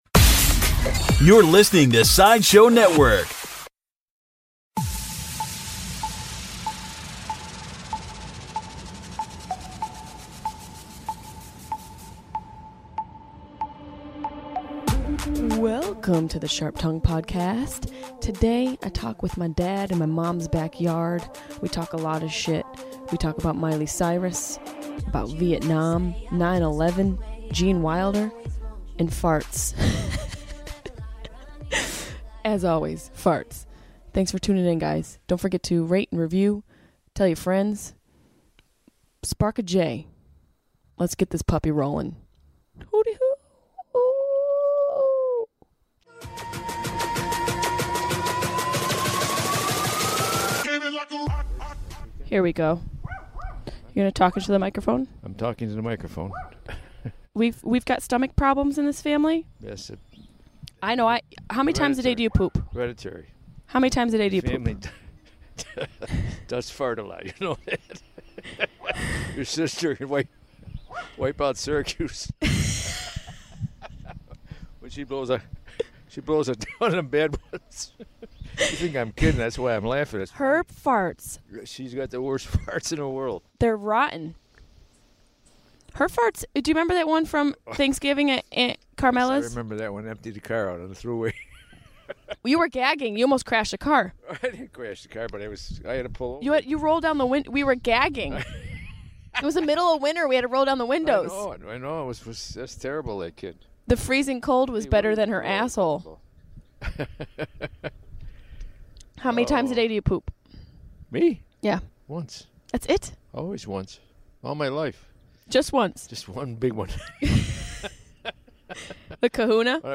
Recorded from my Mom’s backyard in Syracuse